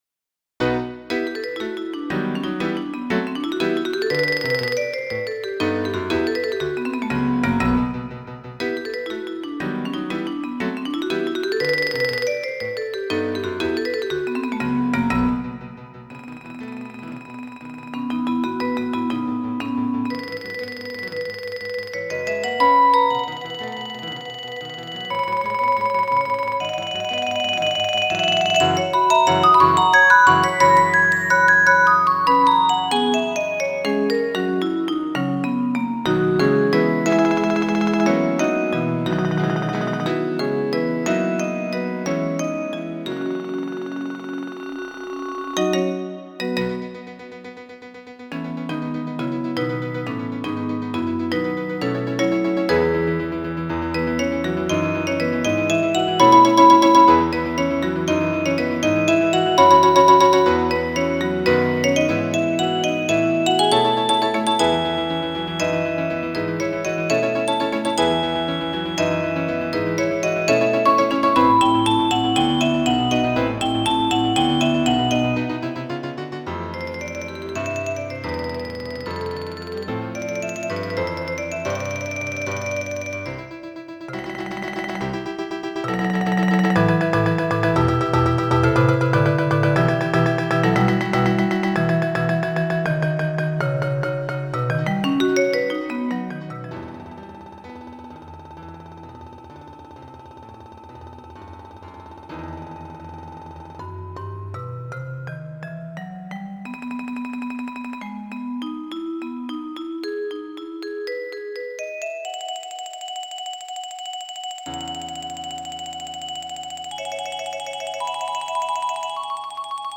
Genre: Duet for Marimba & Piano